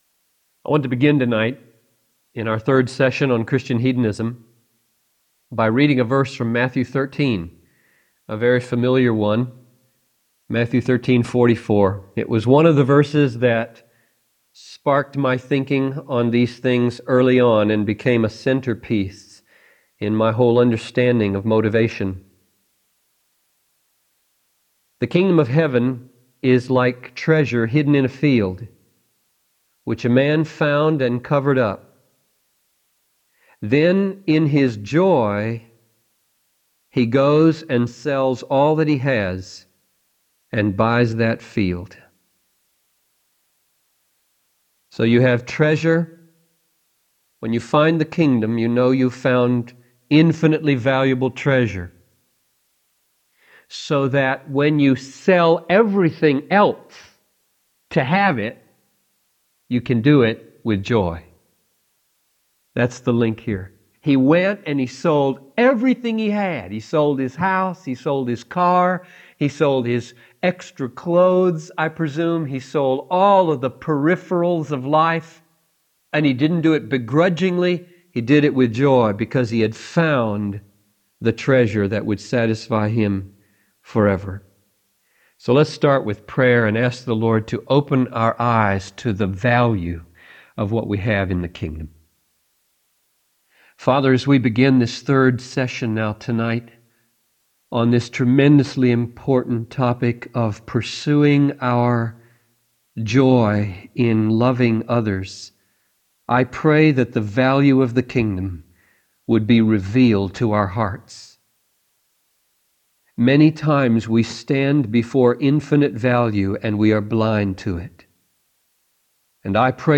Message by John Piper